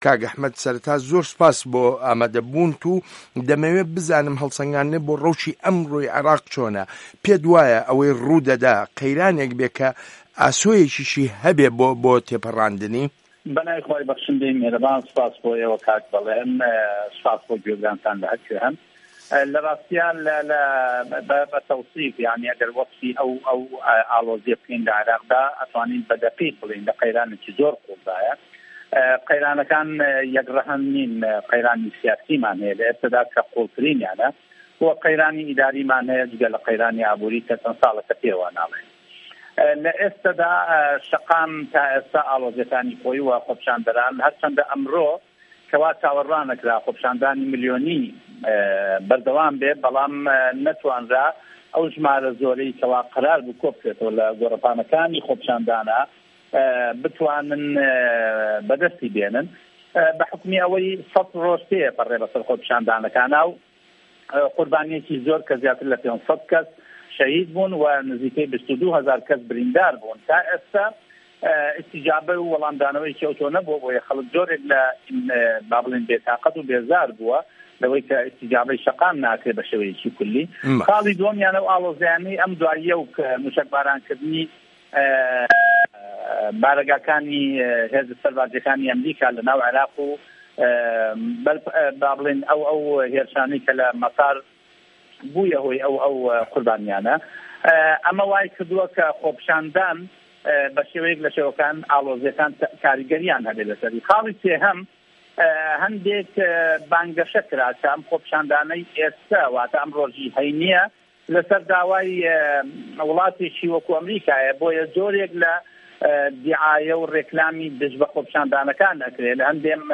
وتووێژ لەگەڵ ئەحمەد حاجی ڕەشید